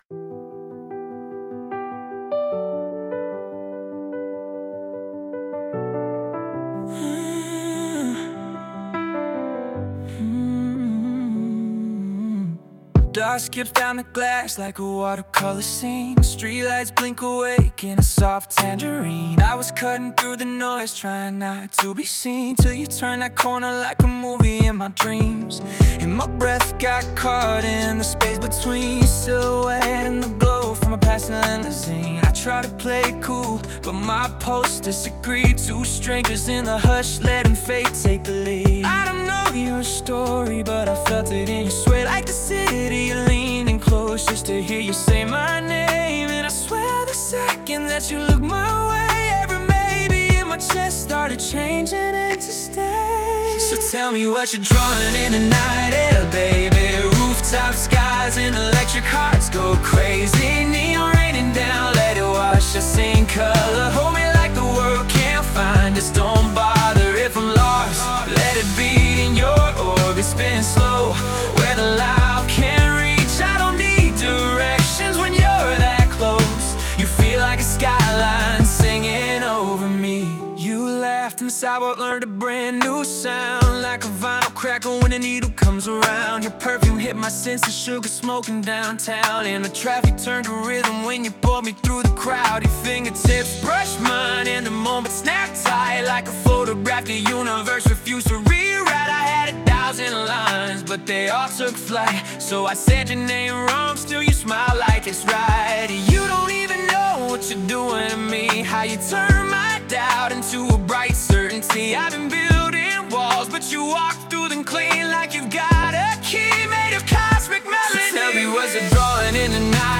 Pop 2026 Non-Explicit